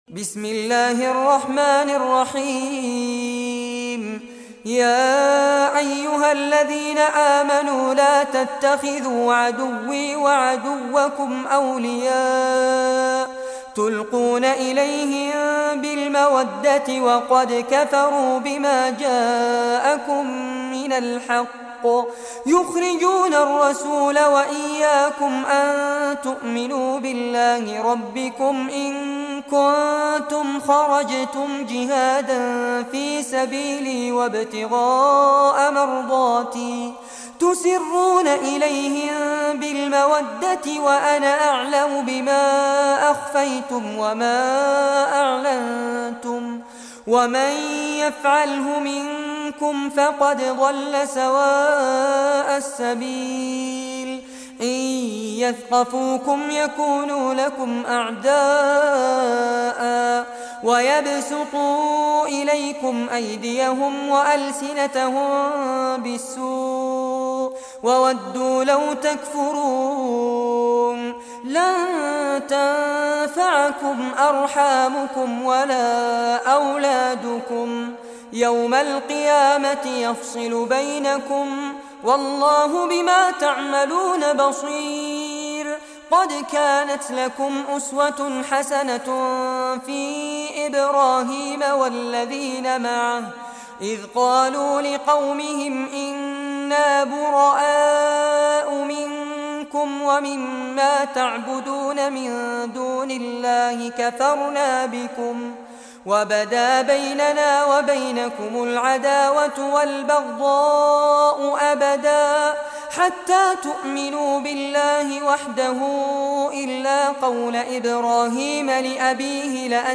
Surah Al-Mumtahanah Recitation by Fares Abbad
Surah Al-Mumtahanah, listen or play online mp3 tilawat / recitation in Arabic in the beautiful in the voice Sheikh Fares Abbad.